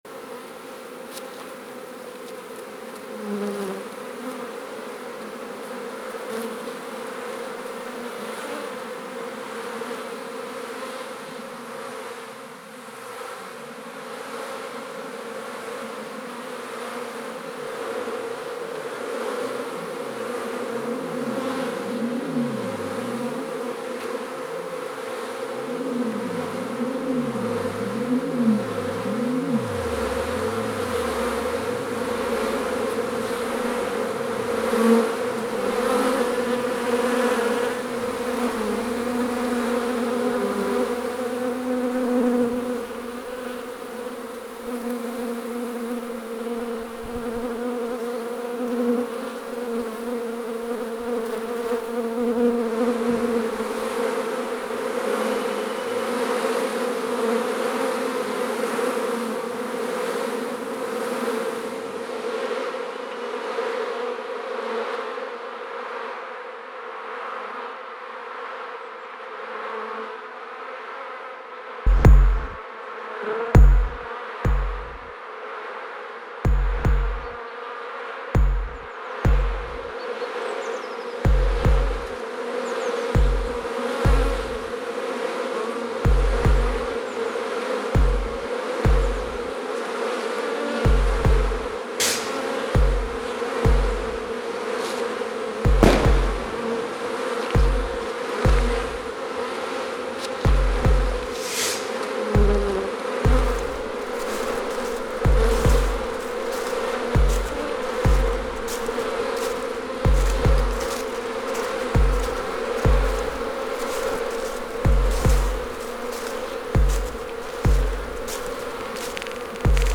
Den Recorder habe ich auf einen Bienenstock gelegt. Im „Ort“ gibt es stille Schafe und Hühner (gesprächiger) und jede Menge Laub auf den Wegen.
Die Drums sind die Hölzer am Weg. Geklopft, gerührt und geschlagen.
Was sind Soundscapes